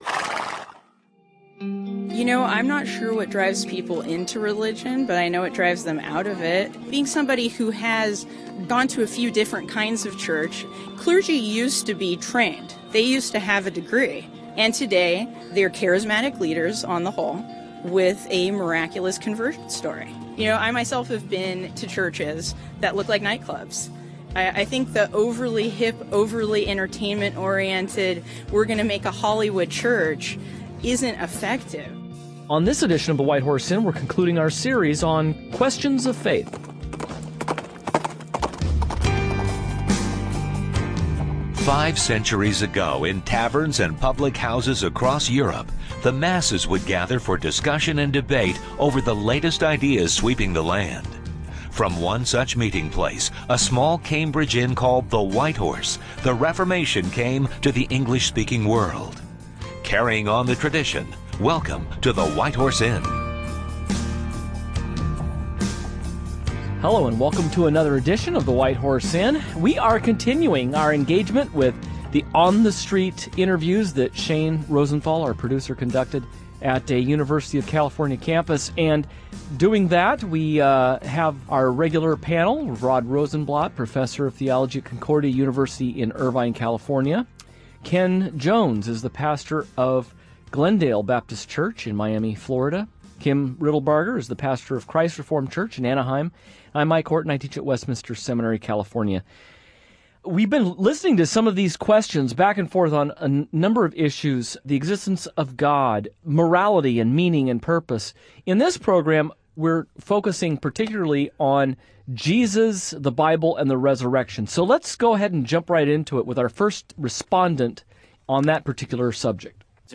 The hosts will discuss these issues as they interact with the views and opinions of today’s college students in the fourth…